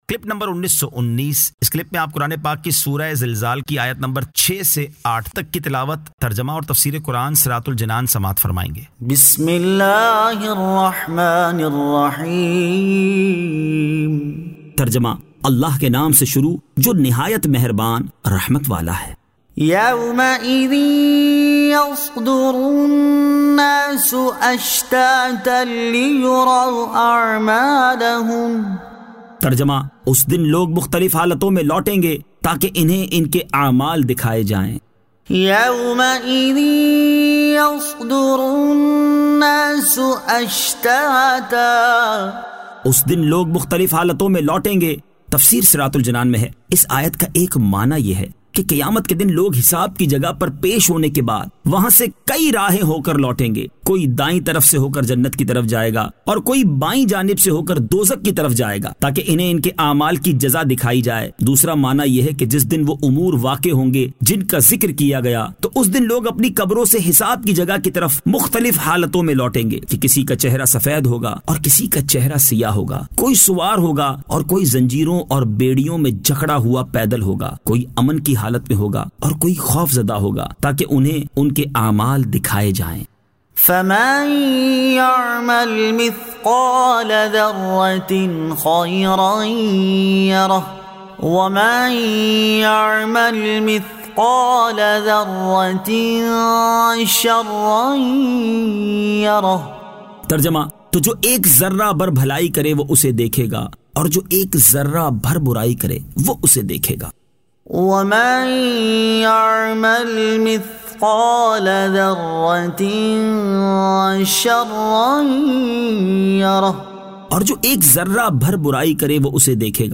Surah Al-Zilzal 06 To 08 Tilawat , Tarjama , Tafseer